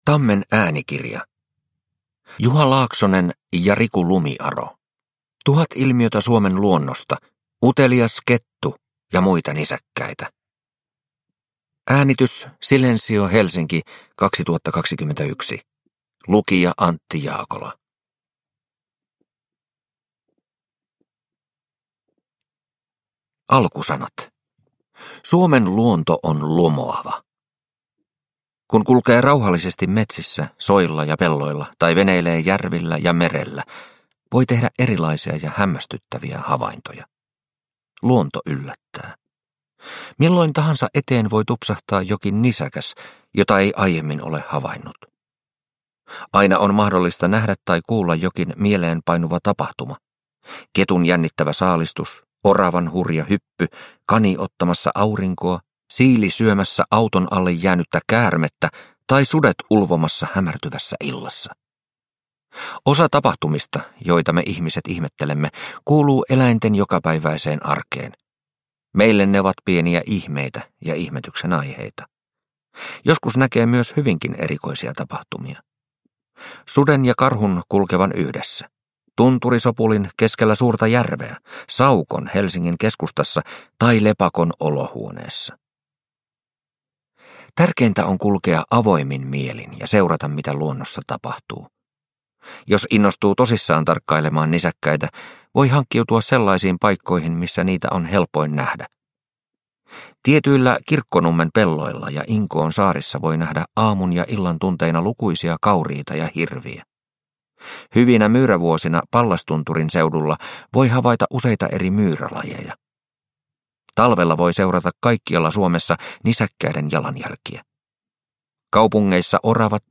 Utelias kettu ja muita nisäkkäitä – Ljudbok – Laddas ner